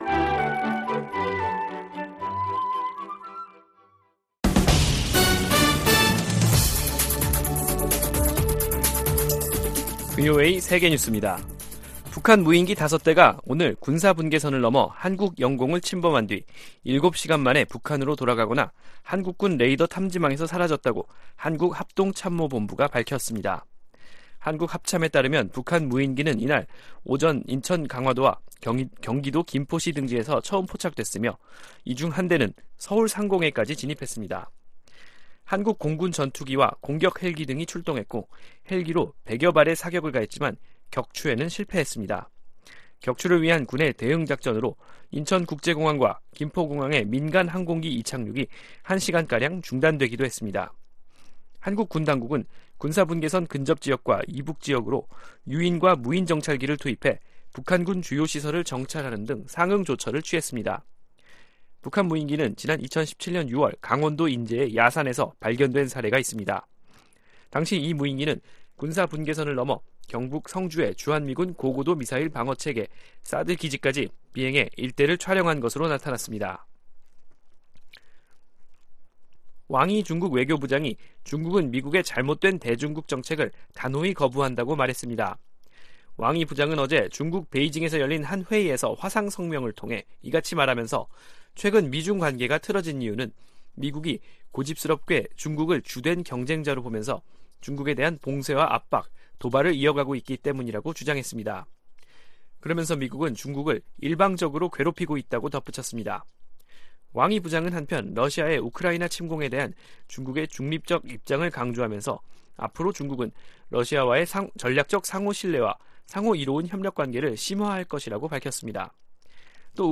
VOA 한국어 간판 뉴스 프로그램 '뉴스 투데이', 2022년 12월 26일 3부 방송입니다. 북한 무인기가 오늘 5년 만에 남측 영공을 침범해 한국군이 격추 등 대응작전을 벌였지만 격추에는 실패했습니다. 미국 국무부가 북한 정권의 단거리탄도미사일 발사를 규탄하면서 이번 발사가 유엔 안보리 결의에 위배된다고 지적했습니다.